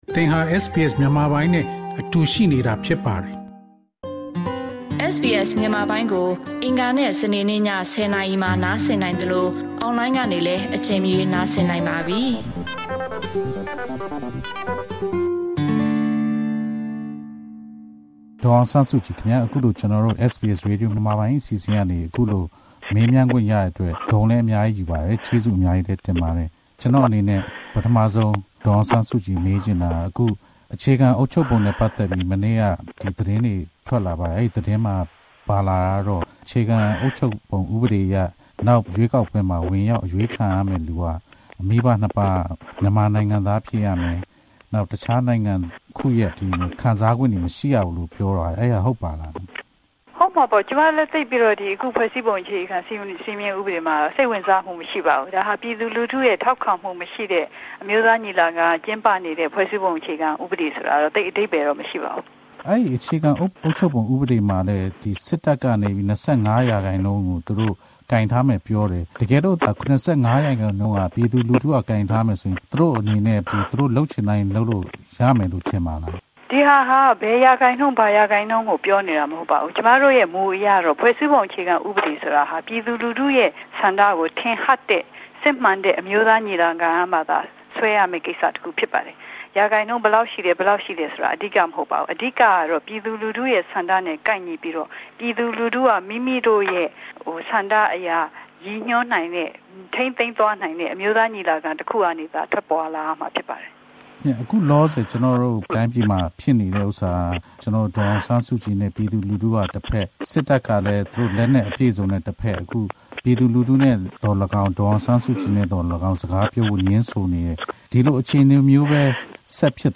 ၁၉၉၆ ခုနှစ်က ဒေါ်အောင်ဆန်းစုကြည်ကို မေးမြန်းထားခန်း။
၁၉၉၅ ခုနှစ် ဇူလိုင်လ ၁၀ ရက်နေ့တွင် နဝတက ဒေါ်အောင်ဆန်းစုကြည်ကို နေအိမ်အကျယ်ချုပ် မှလွှတ်ပေး ခဲ့ပြီး ၁၉၉၆ ခုနှစ် မြန်မာ့နှစ်သစ်ကူးနေ့တွင် ဒေါ်အောင်ဆန်းစုကြည်ကို SBS မြန်မာပိုင်းအစီအစဉ်က ဆက်သွယ် မေးမြန်းခဲ့ပါသည်။